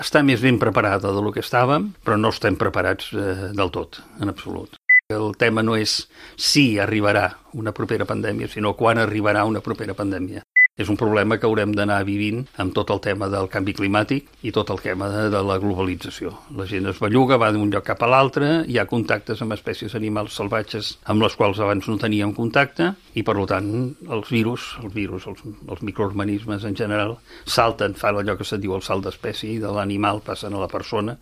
L’HORA DE LA GENT GRAN ha entrevistat el calellenc Joan Guix, qui va ser secretari de Salut Pública de la Generalitat de Catalunya durant la primera onada de la pandèmia de la Covid, que ha recordat com un moment d’extrema duresa en què l’equip polític i tècnic que estava al capdavant de la gestió de la crisi al país va sentir molta impotència.